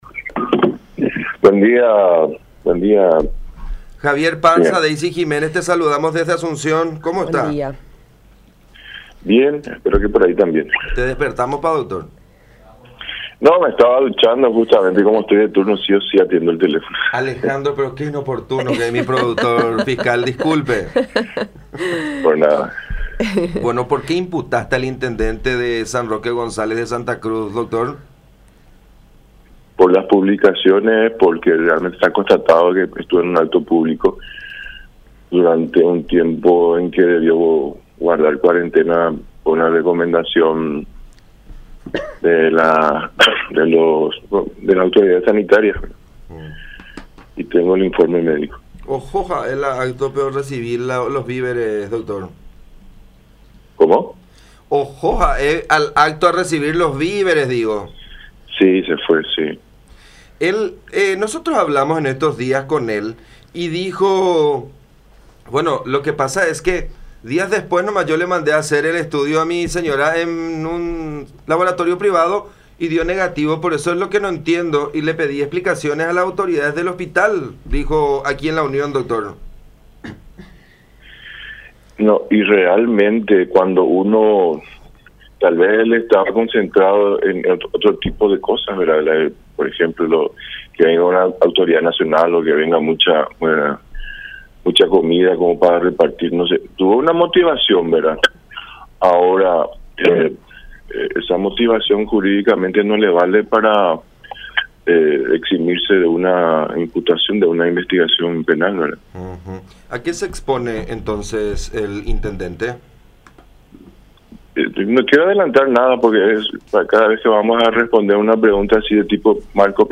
“Hicimos esta imputación en base a las informaciones médicas que recibimos”, afirmó el fiscal del caso, Rodolfo Colmán, en contacto con La Unión.